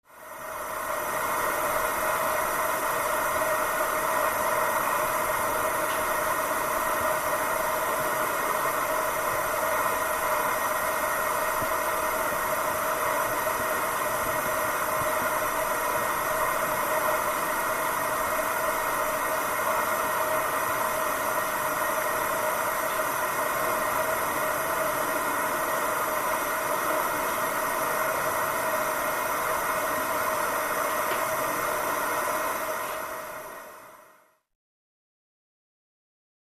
Pipes: Water Hissing Through, With Clicks.